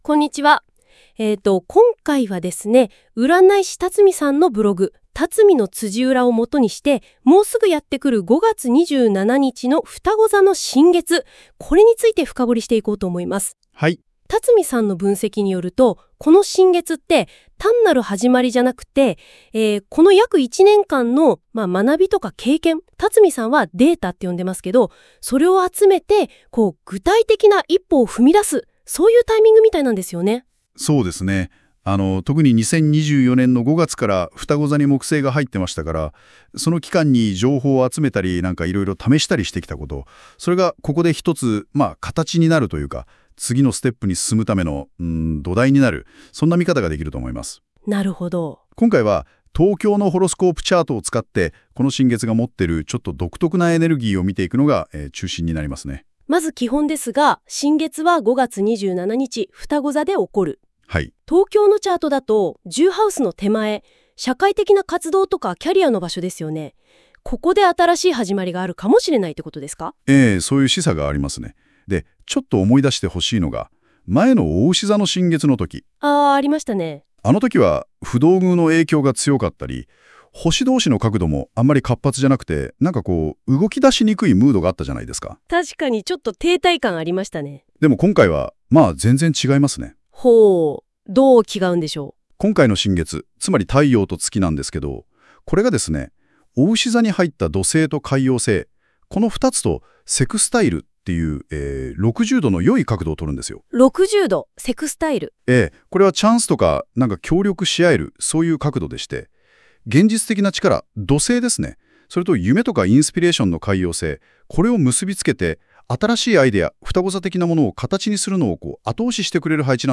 今回、実験的にnotebookLMで音声概要をしてみました。